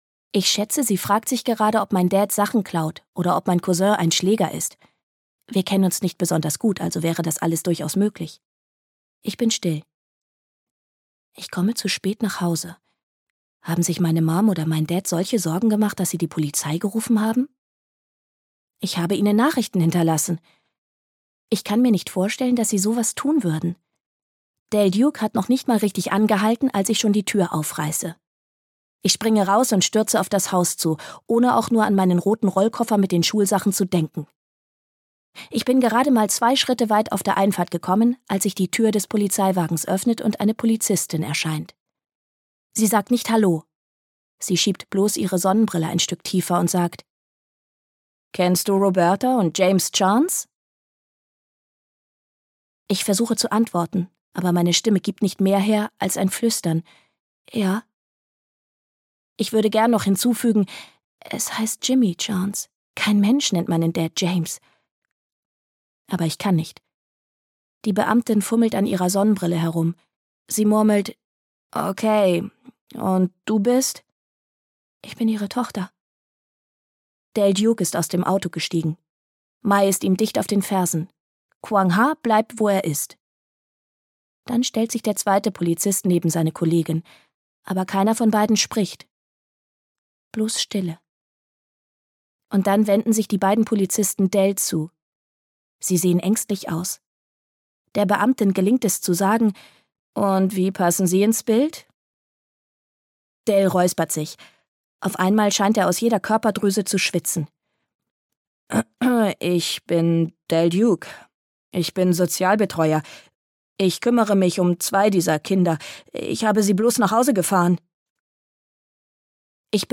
Hörbuch Glück ist eine Gleichung mit 7, Holly Goldberg Sloan.